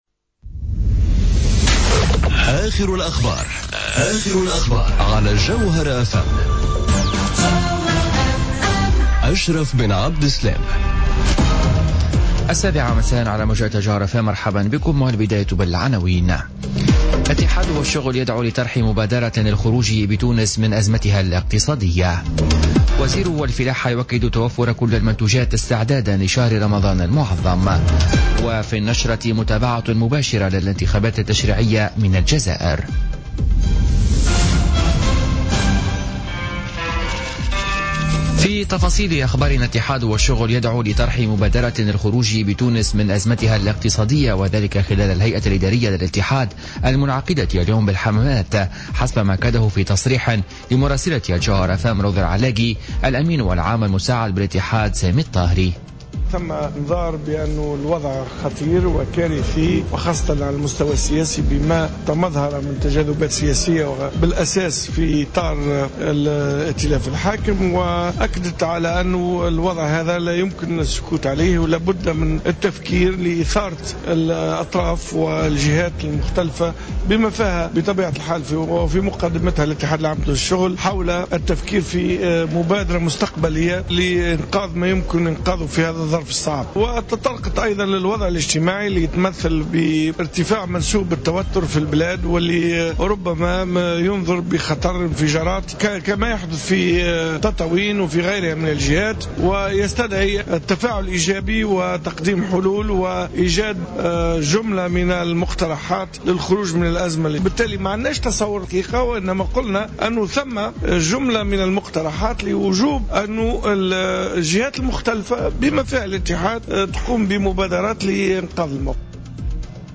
نشرة أخبار السابعة مساء ليوم الخميس 4 ماي 2017